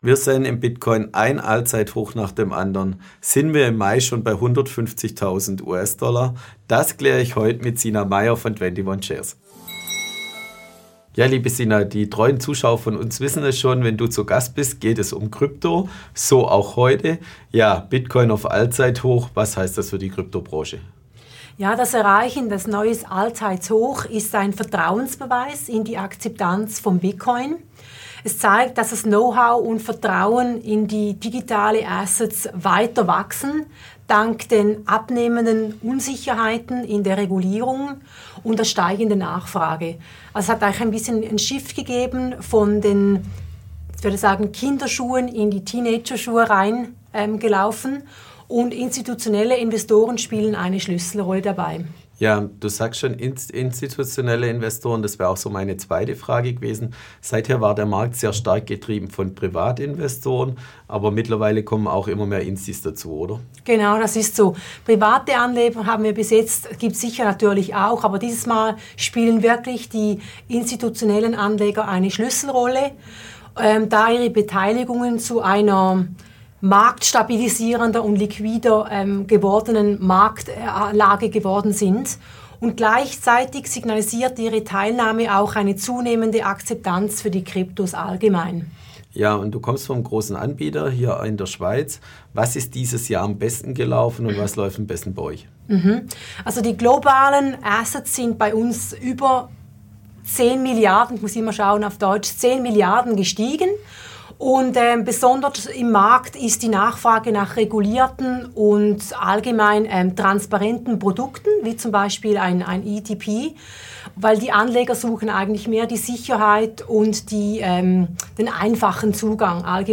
heutigen Experteninterview.